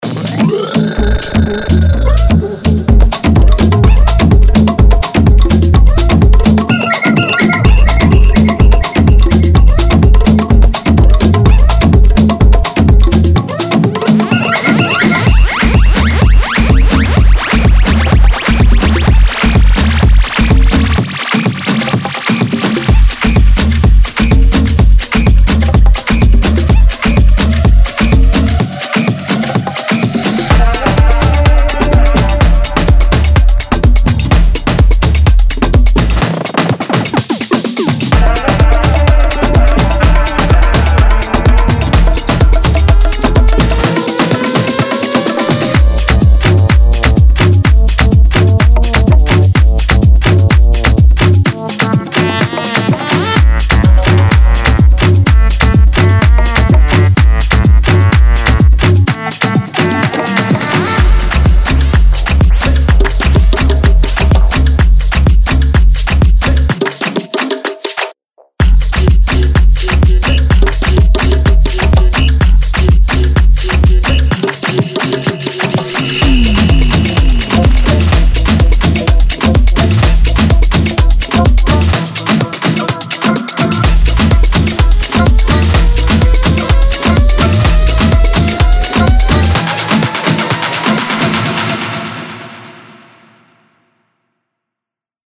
With its broken-down drum loops, tight bass grooves, and Latin-themed melodic hooks, this pack is a treasure trove for those seeking to infuse their productions with some Latin flair.
43 x Full Drum Loops
49 x Perc Loops
28 x Shaker Loops
30 x Bass Loops
30 x Synth Loops